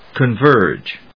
音節con・verge 発音記号・読み方
/kənvˈɚːdʒ(米国英語), kənvˈəːdʒ(英国英語)/